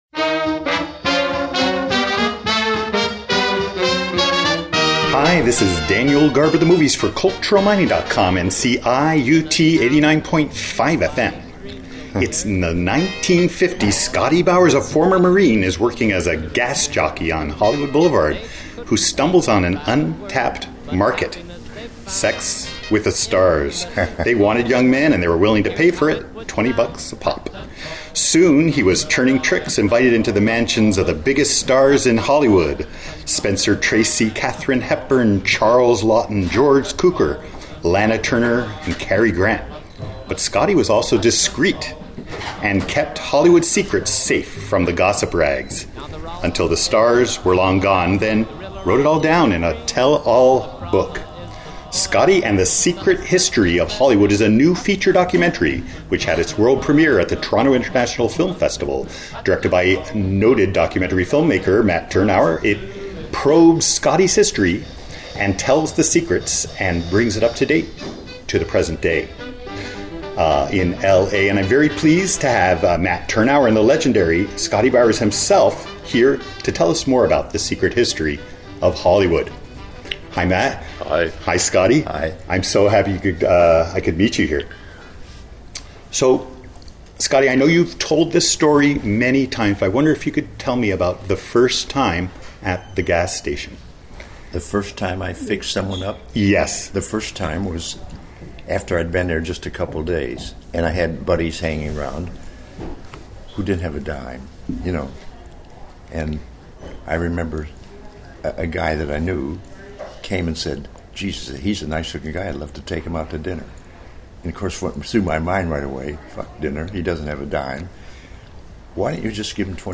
I spoke with Matt Tyrnauer and the legendary Scotty Bowers at TIFF17.